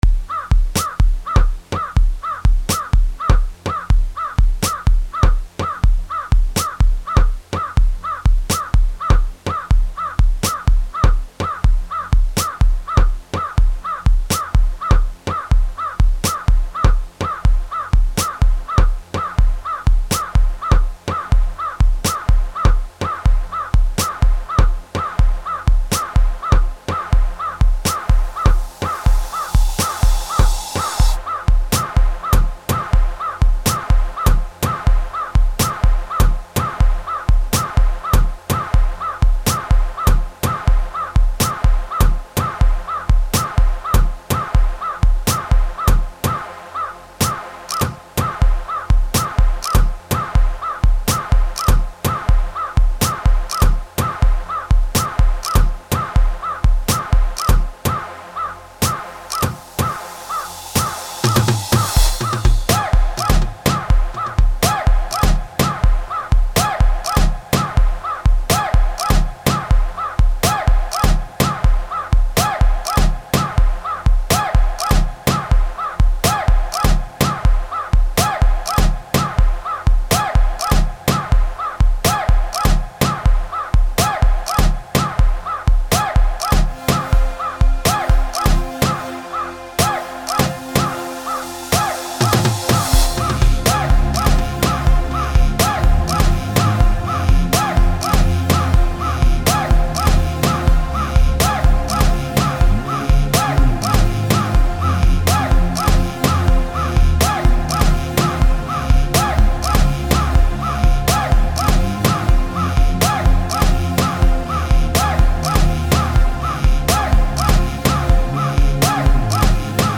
07:33 Genre : Gqom Size